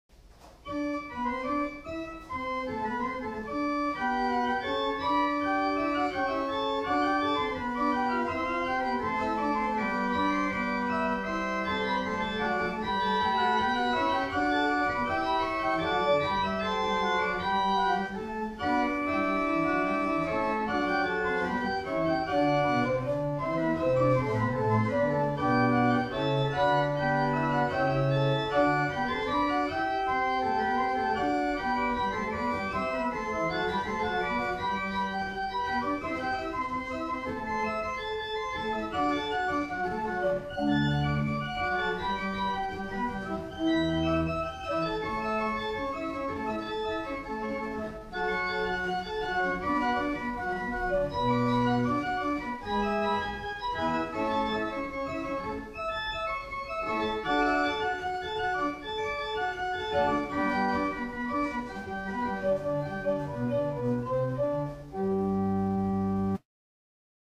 1802 Tannenberg Organ
Hebron Lutheran Church - Madison, VA